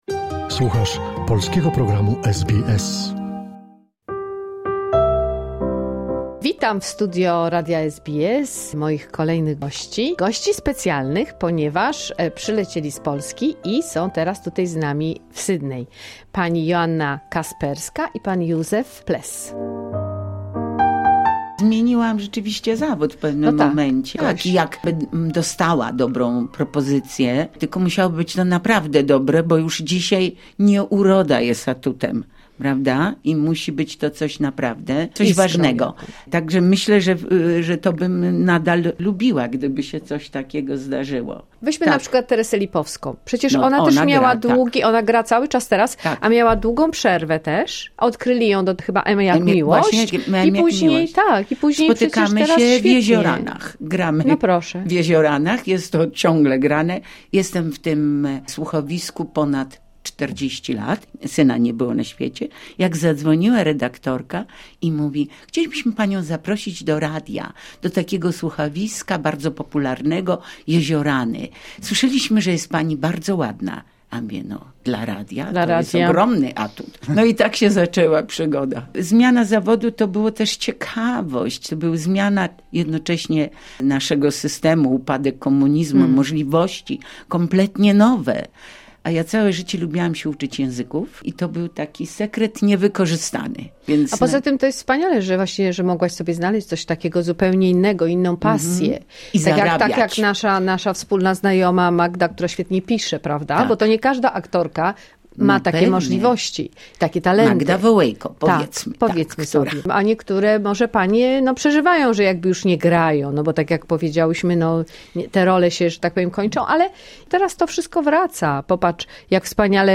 Trzecia część rozmowy z wyjątkową parą z Polski - aktorką i poetą.